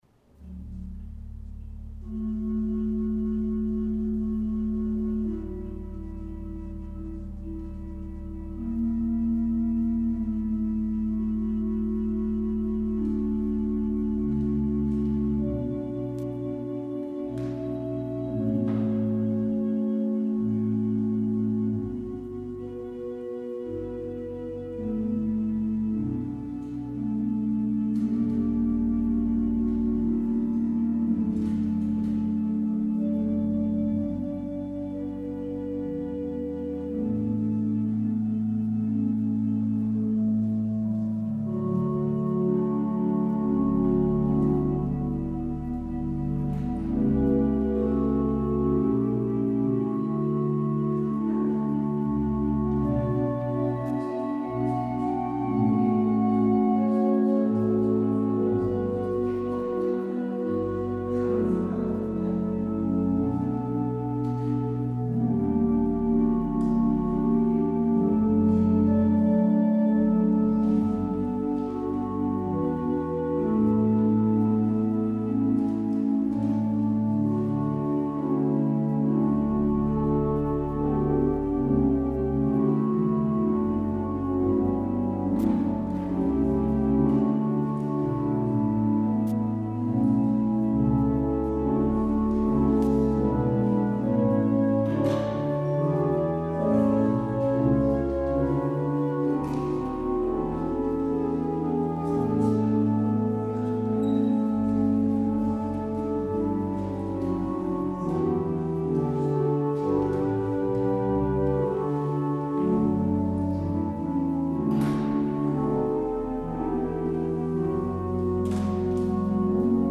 Luister deze kerkdienst hier terug: